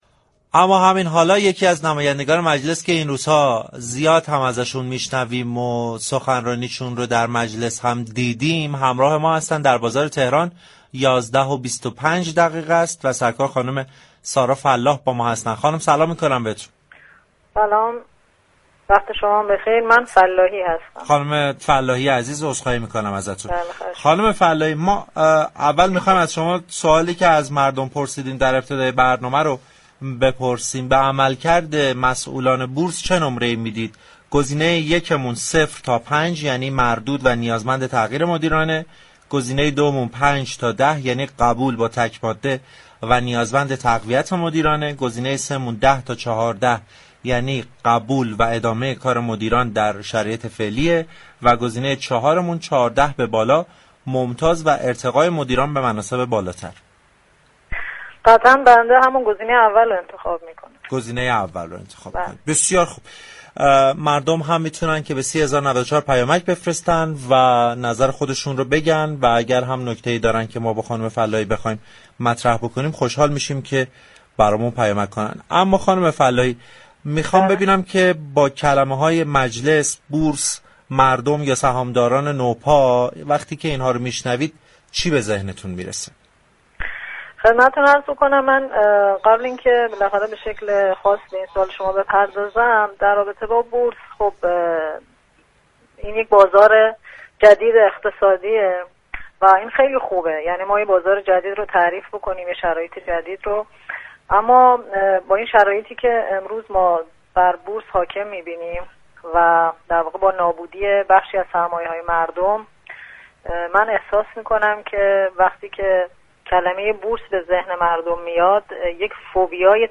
سارا فلاحی نماینده مردم ایلام در مجلس یازدهم در گفتگو با برنامه بازار تهران و عضو كمیسیون امنیت ملی و سیاست‌خارجی، عملكرد مسئولان بورس را ضعیف خواند و نیاز به تعویض مسئولان را جدی دانست.